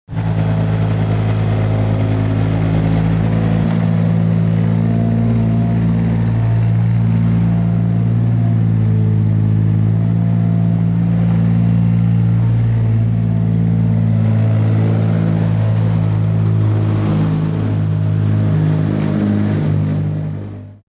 دانلود آهنگ تراکتور 2 از افکت صوتی حمل و نقل
دانلود صدای تراکتور 2 از ساعد نیوز با لینک مستقیم و کیفیت بالا
جلوه های صوتی